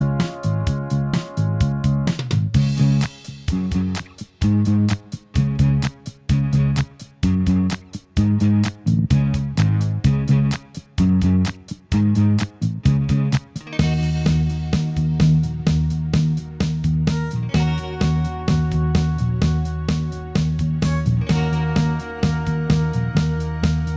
Minus Lead Guitar Pop (1970s) 2:57 Buy £1.50